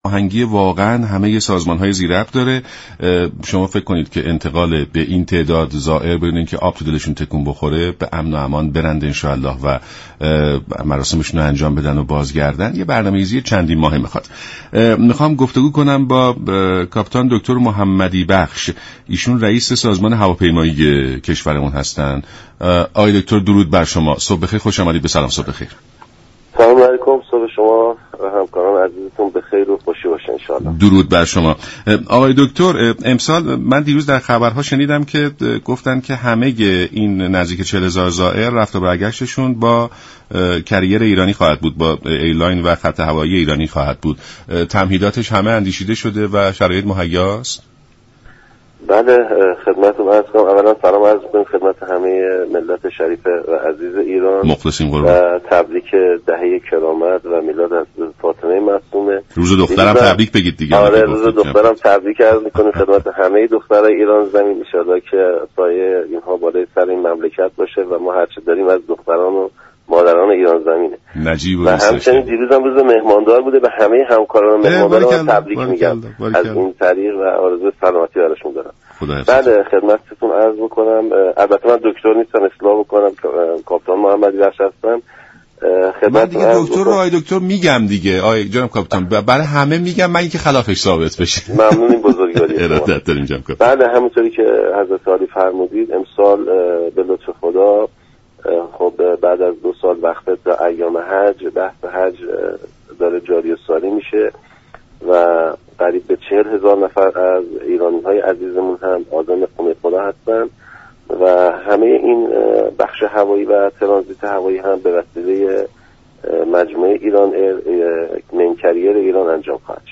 به گزارش شبكه رادیویی ایران،«محمد محمدی بخش» رییس سازمان هواپیمایی كشور در برنامه «سلام صبح بخیر» رادیو ایران درباره تمهیدات اندیشیده شده برای اعزام زائران ایرانی به خانه خدا گفت: پس از دو سال وقفه به دلیل شیوع ویروس كرونا، امسال 40 هزار زائر ایرانی با پروازهای ایران‌ایر از 18 فرودگاه كشور به خانه خدا مشرف می شوند.